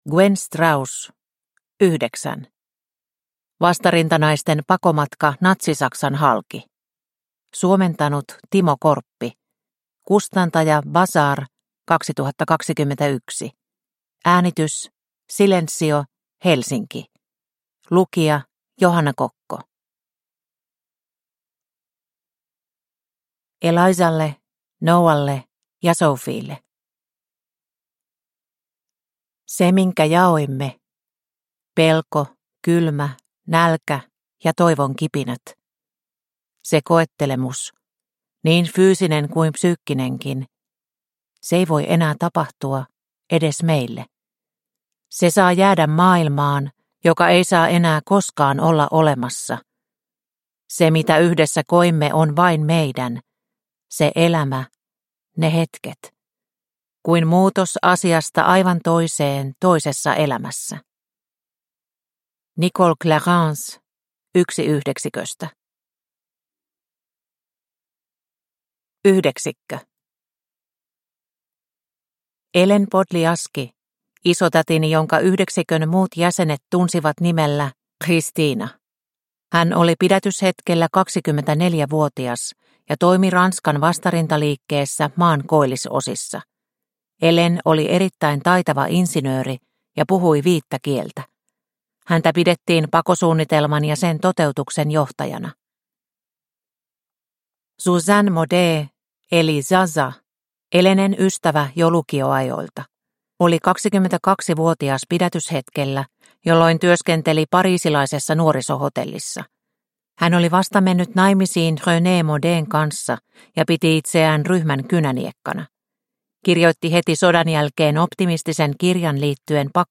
Yhdeksän – Ljudbok